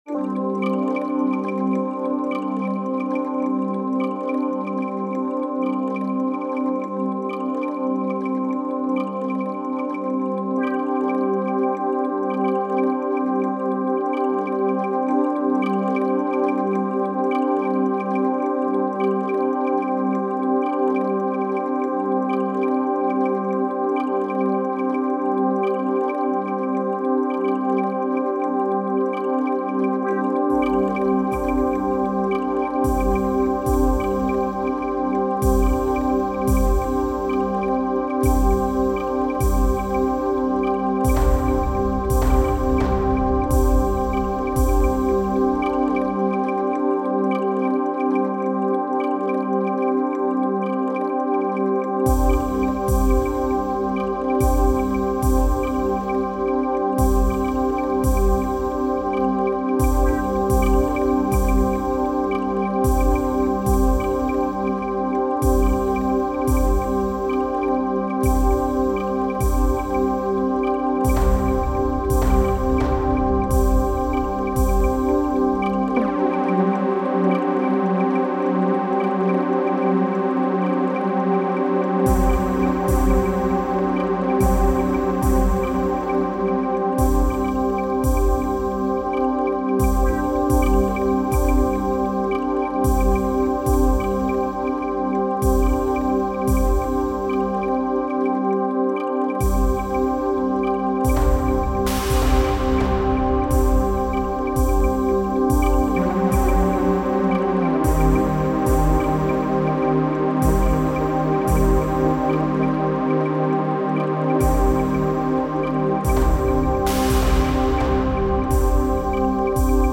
atmospheric